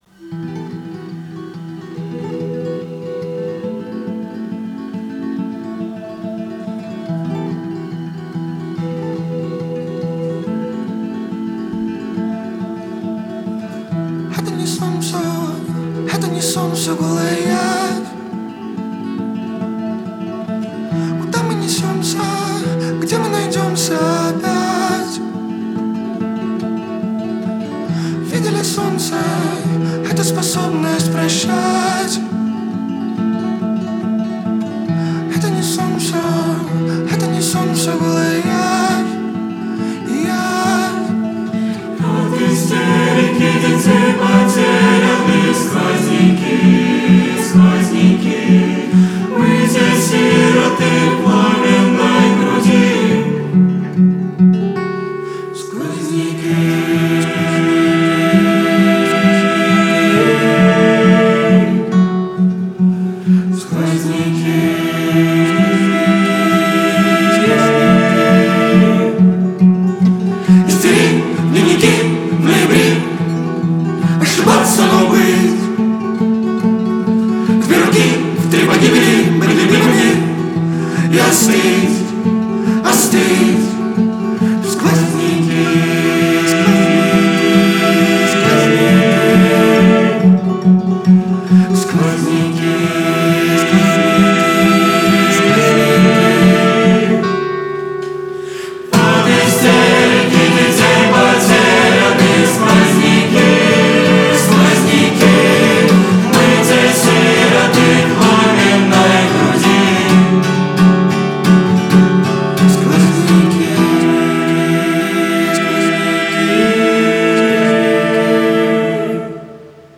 Категория: Хип-Хоп